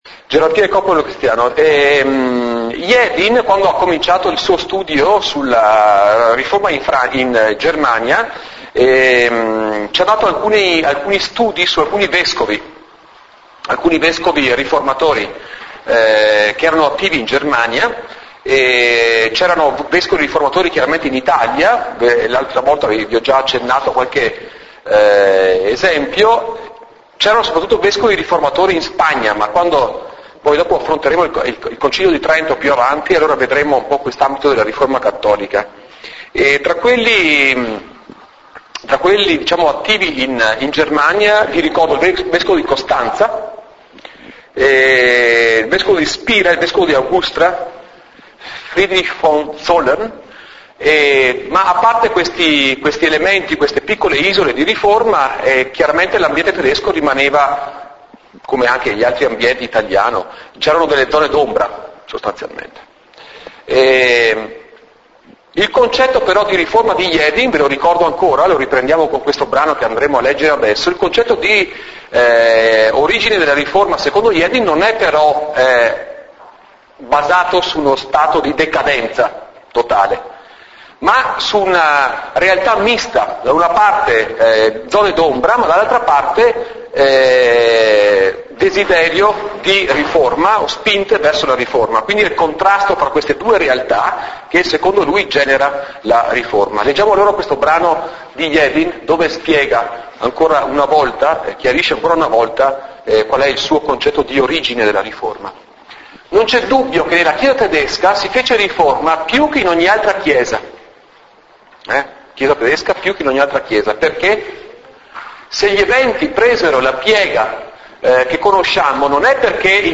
In Italian: 06� lezione: Rottura tra Gerarchia e Popolo in Germania